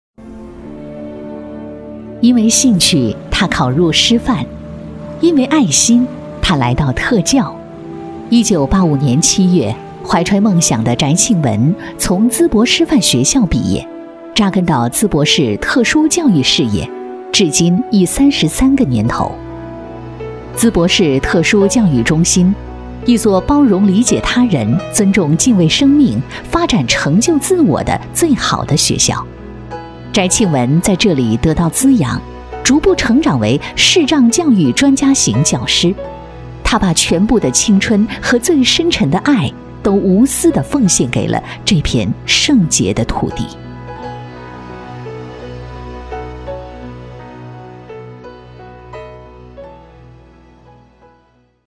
• 女S114 国语 女声 宣传片 最美老师 人物介绍 亲切甜美